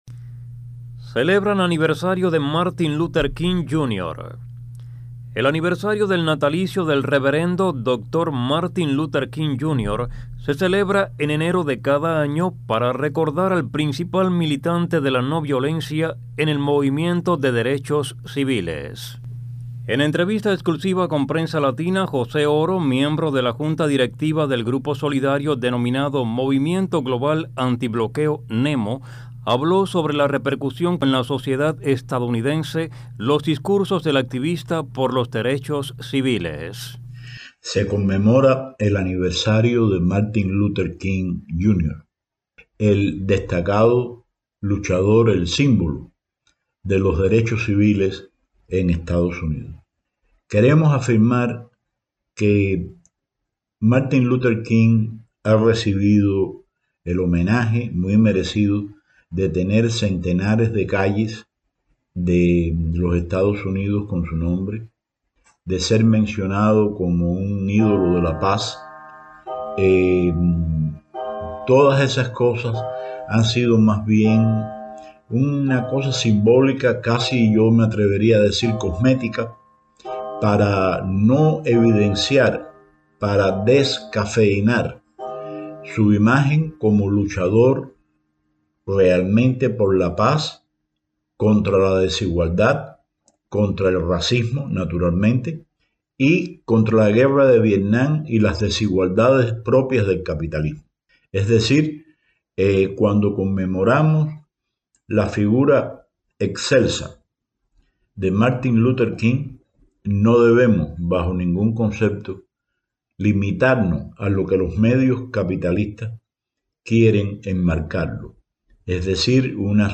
El aniversario del natalicio del reverendo doctor Martin Luther King Jr. se celebra en enero de cada año, para recordar al principal militante de la no violencia en el movimiento de derechos civiles. En entrevista exclusiva con Prensa Latina